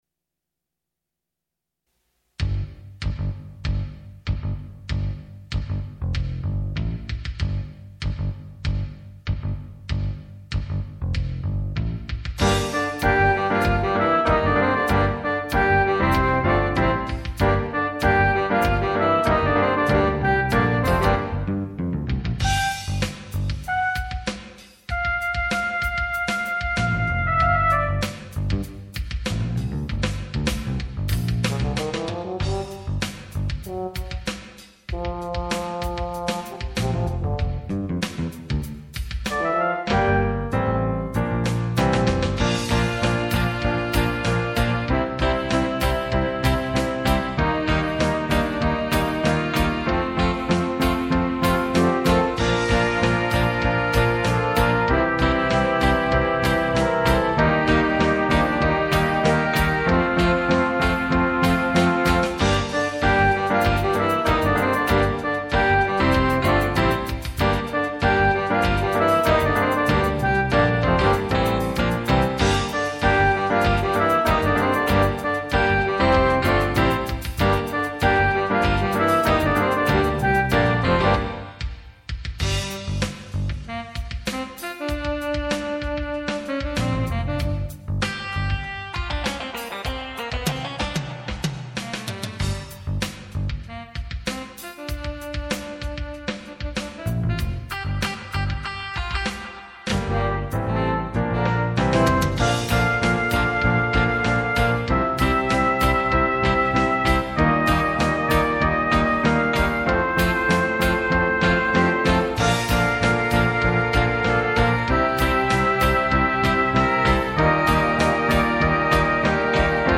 Instrumentation: C Bb, Eb, Gtr, pno, bs, drums
Jazz-funk 8 piece band
All parts contain short solo breaks for each instrument.